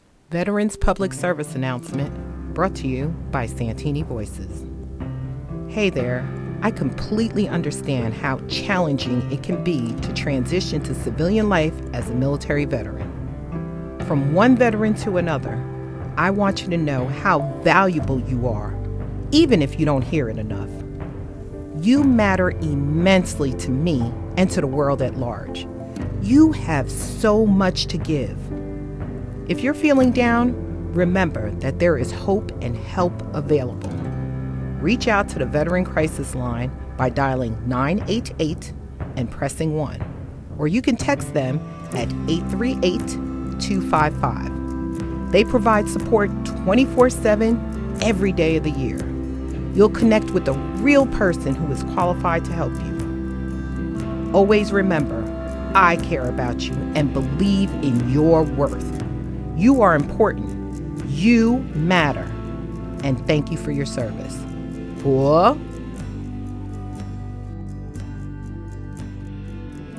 Veterans Public Service Announcement